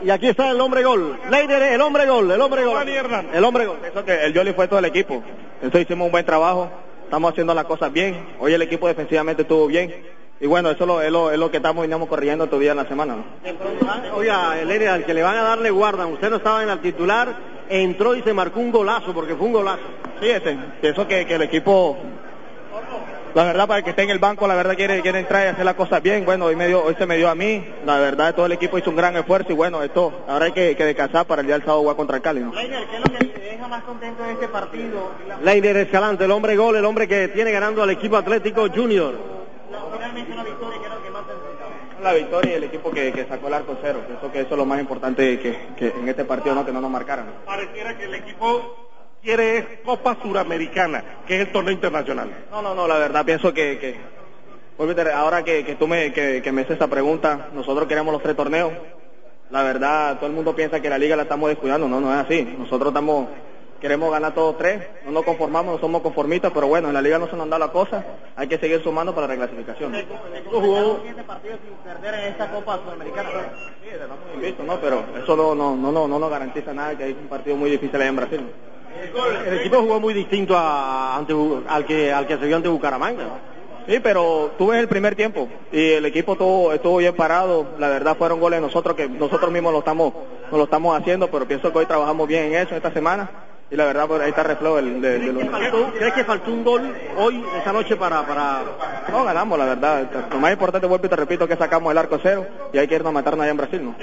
Escuche la reacción de los jugadores tiburones tras su regreso a la victoria.